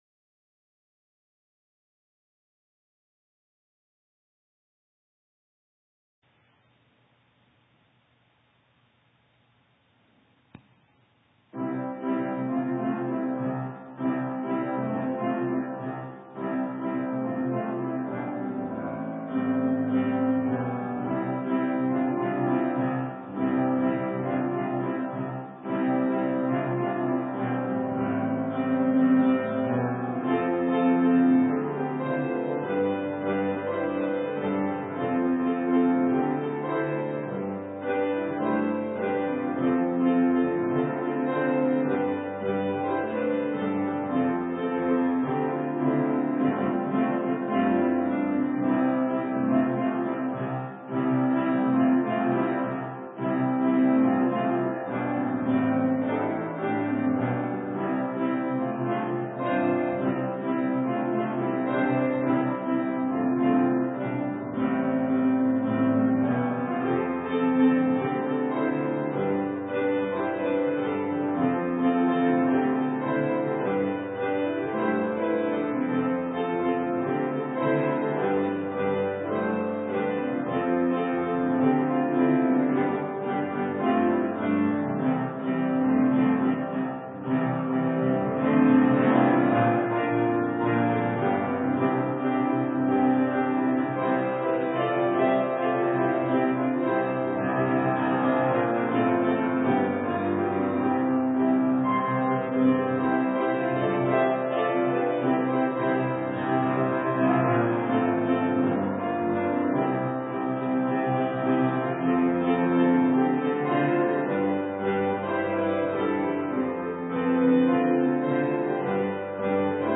Sermon:Looking up into the air - St. Matthews United Methodist Church
The Celebration of the Gospel May 24, 2020 – Ascension Sunday